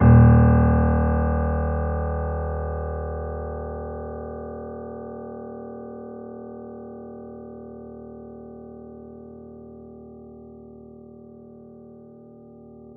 interactive-fretboard / samples / piano / Ds1.wav
Ds1.wav